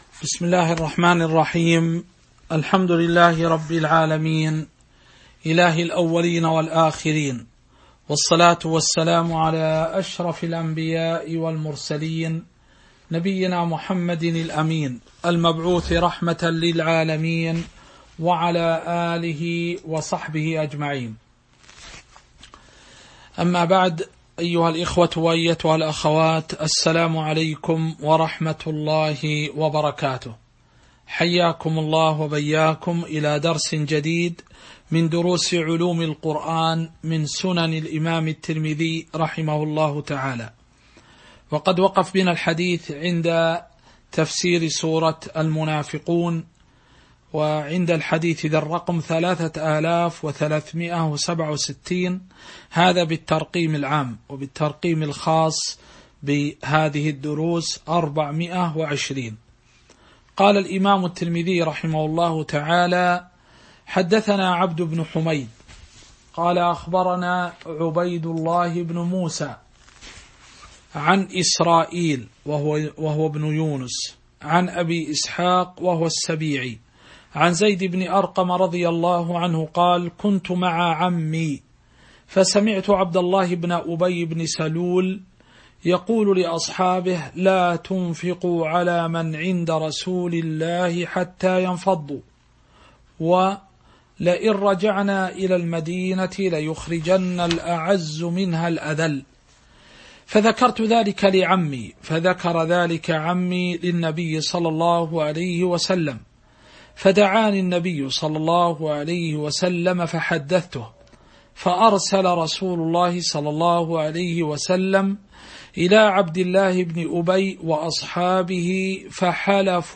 تاريخ النشر ٢٥ رجب ١٤٤٣ هـ المكان: المسجد النبوي الشيخ